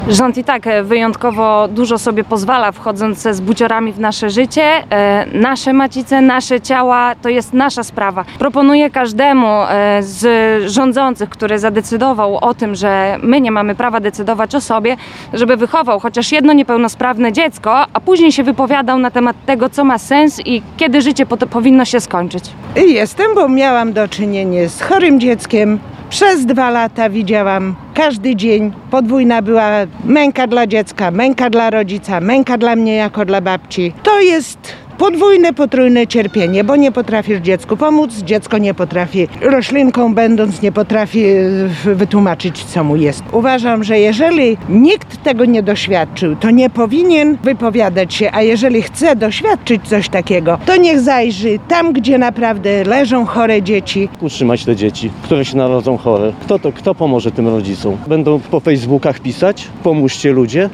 protest2.mp3